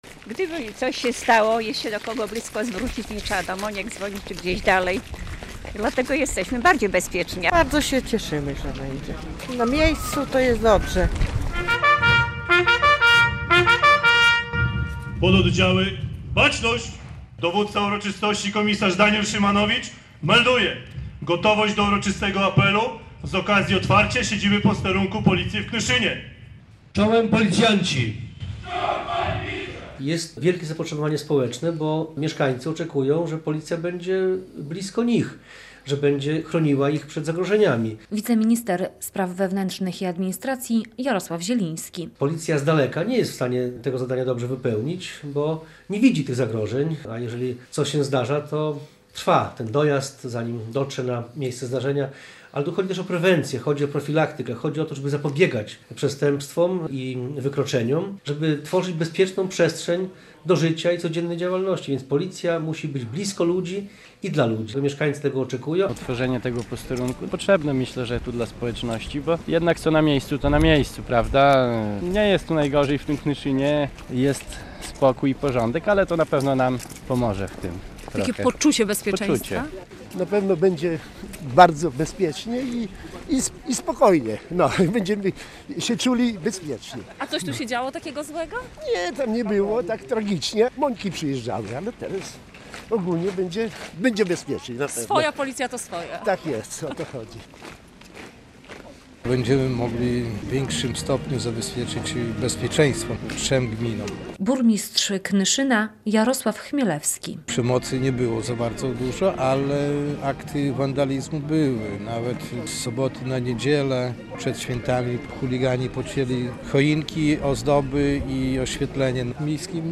W Knyszynie znowu jest otwarty posterunek - relacja